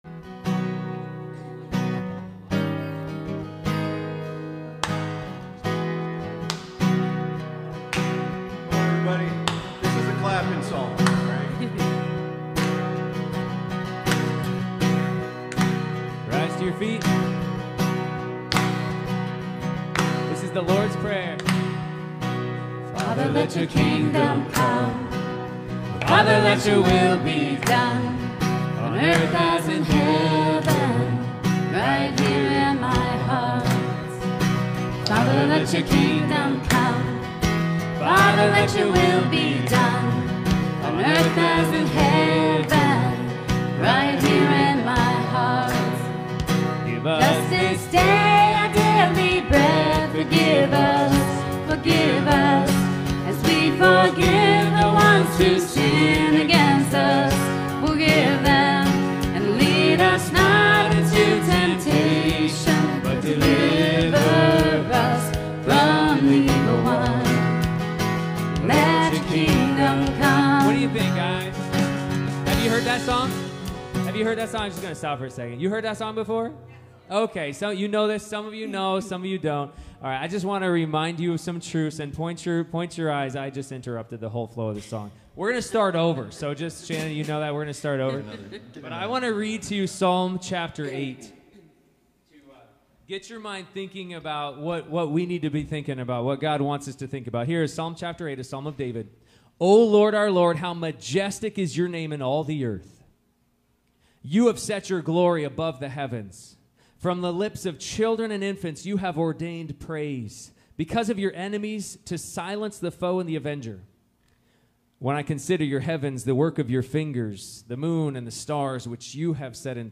Passage: Ephesians 4:29-30 Service Type: Sunday Morning